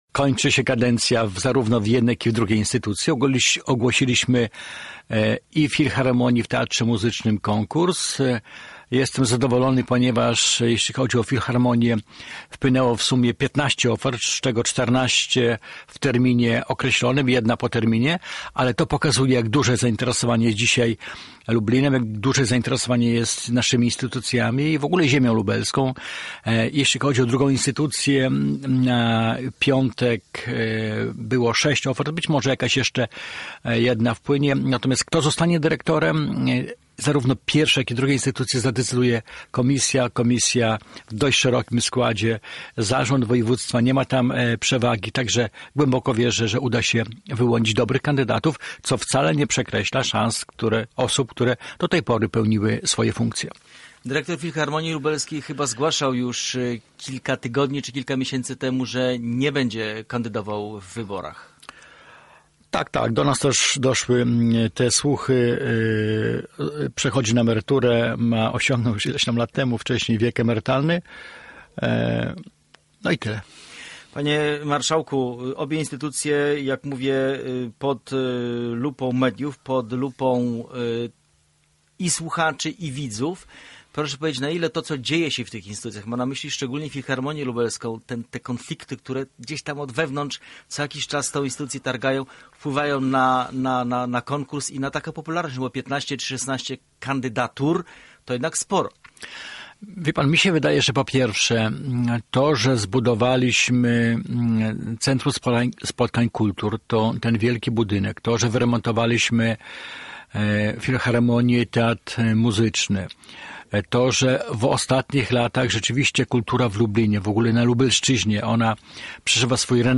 Gościem poniedziałkowego (21.05) Centrum Uwagi był wicemarszałek województwa lubelskiego Krzysztof Grabczuk.